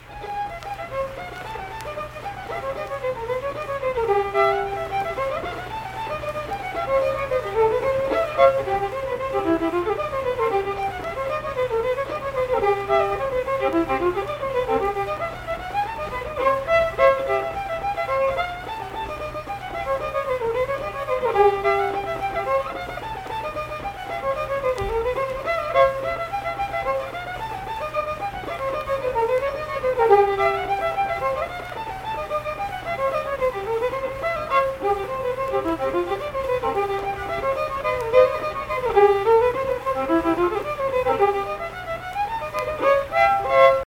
Unaccompanied vocal and fiddle music
Instrumental Music
Fiddle
Saint Marys (W. Va.), Pleasants County (W. Va.)